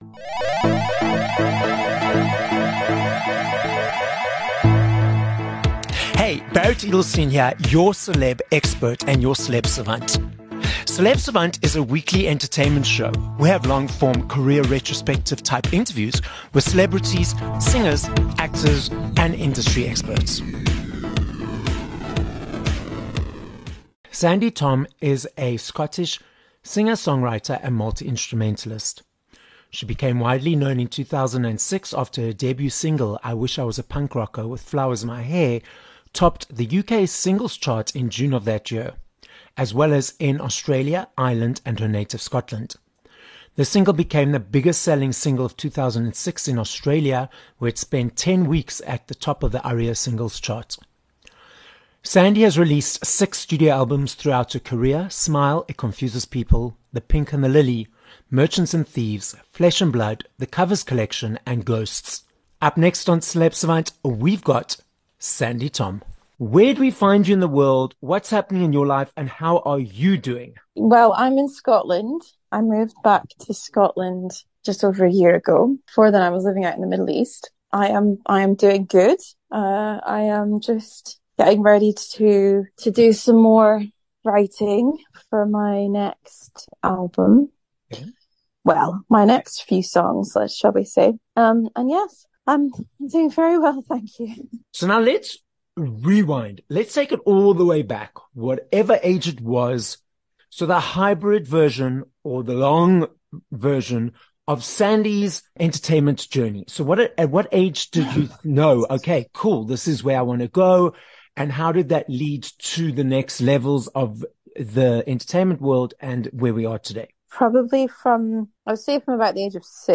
21 Jun Interview with Sandi Thom